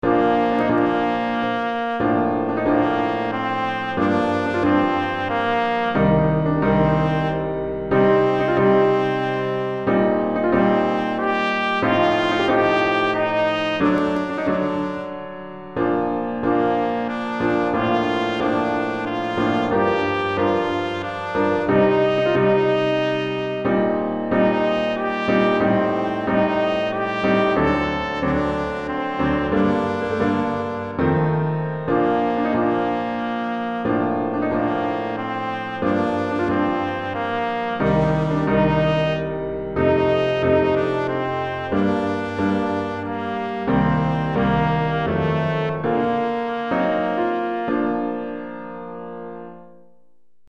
Répertoire pour Trompette ou cornet